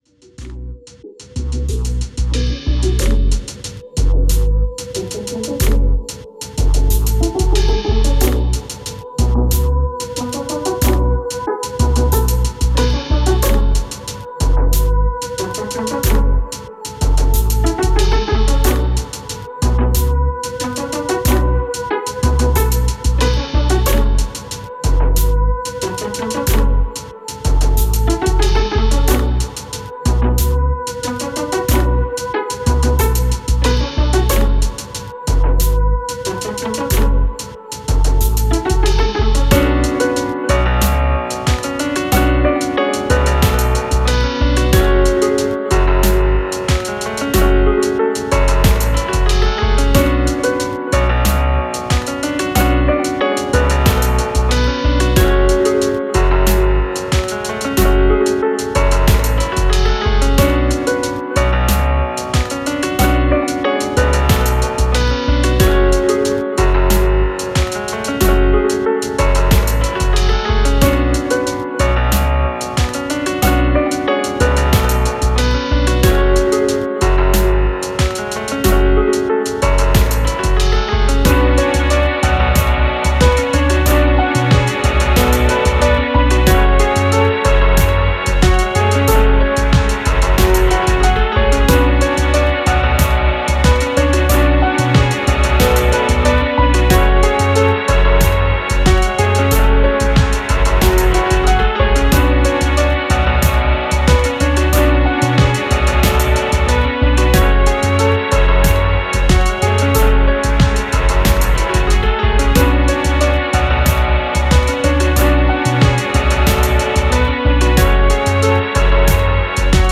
Musique Rap, trap, boombap libre de droit pour vos projets.